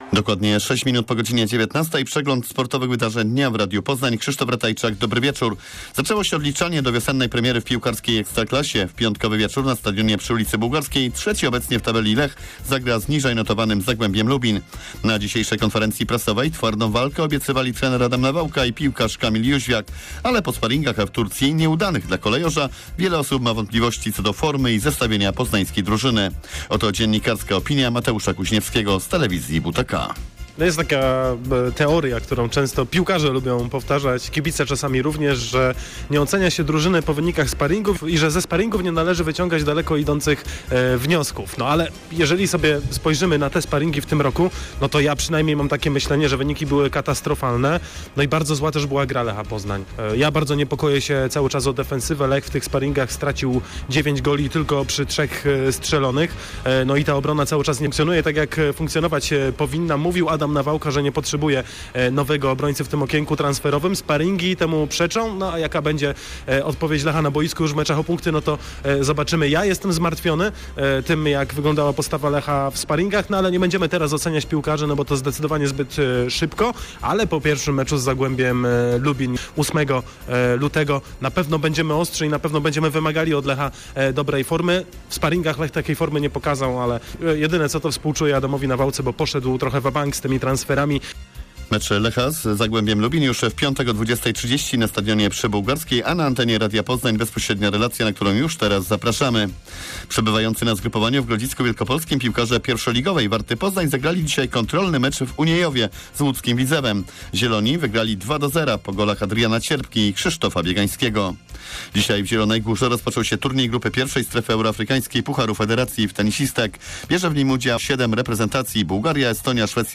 06.02. serwis sportowy godz. 19:05